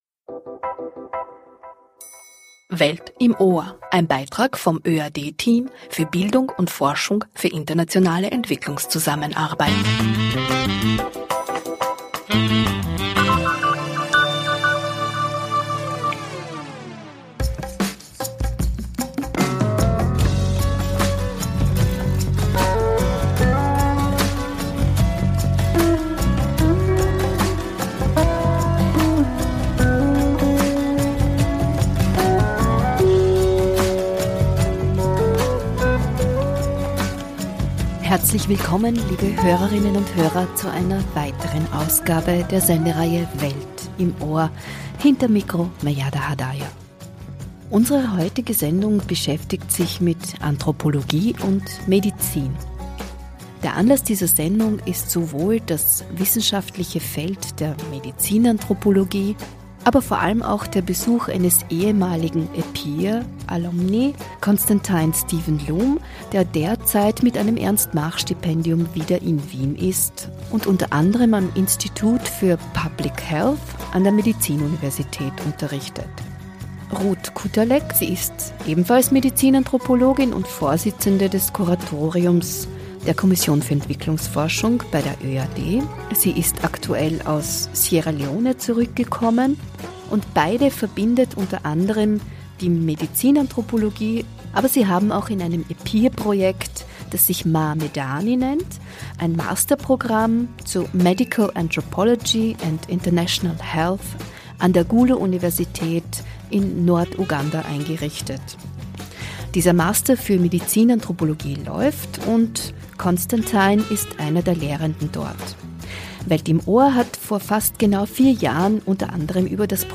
Drei Medizinanthropolog/innen im Gespräch über ihre Arbeit in Afrika und die vielfältigen Facetten der Medizinanthropologie